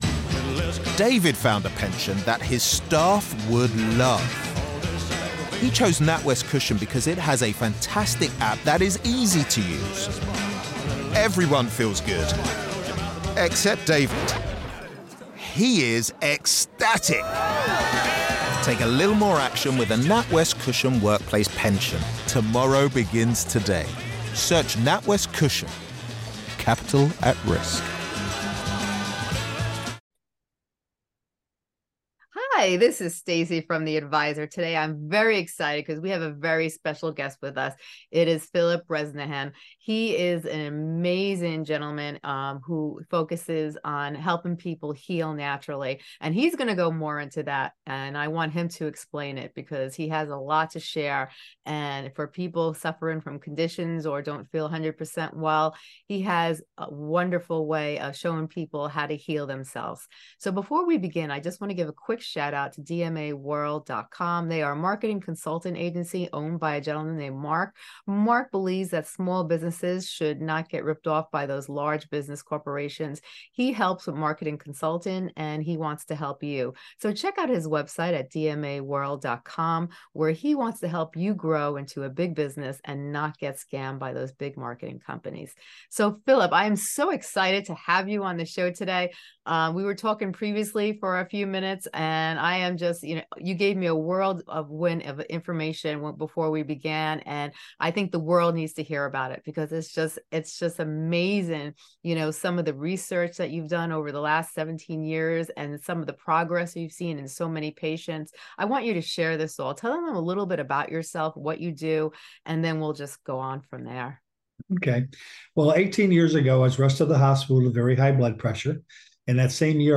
Don't miss out on this eye-opening conversation that has the potential to transform your health and reshape your life.